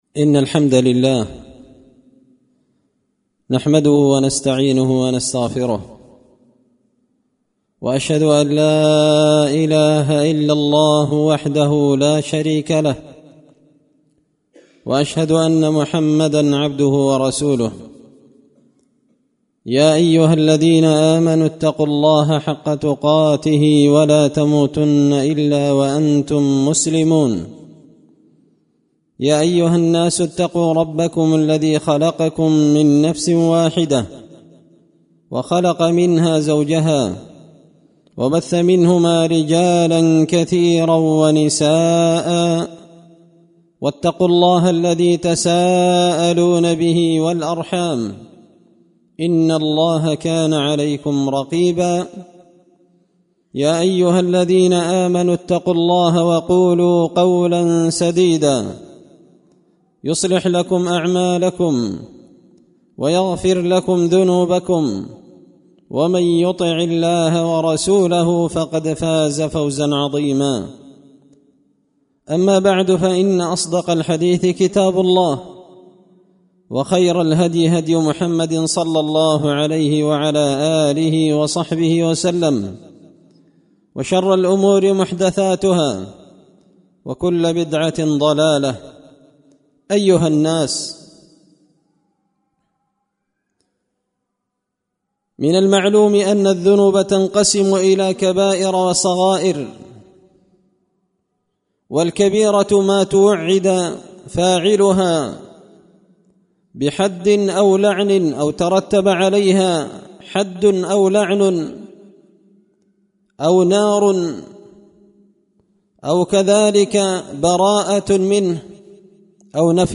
خطبة جمعة بعنوان – شرح احاديث ليس منا
دار الحديث بمسجد الفرقان ـ قشن ـ المهرة ـ اليمن